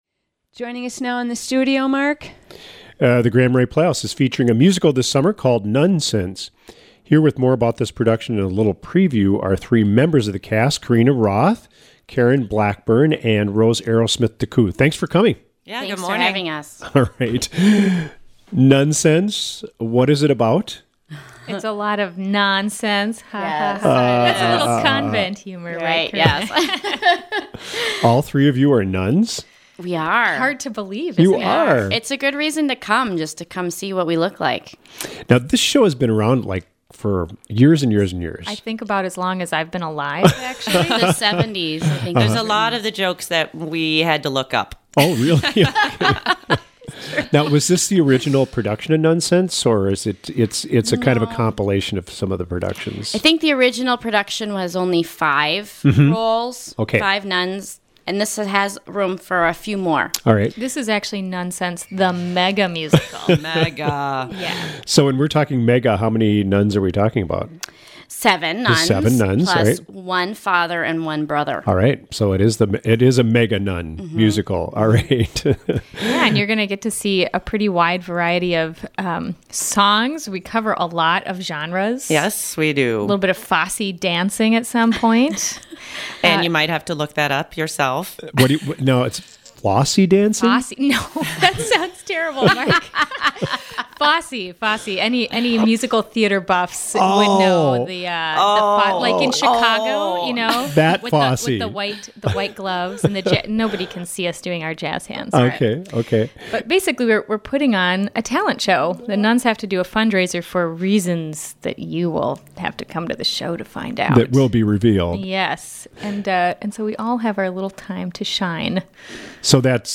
WTIP had cast members of the Grand Marais Playhouse's production of Nunsense in the studio to talk about the upcoming play, and for a preview of the songs.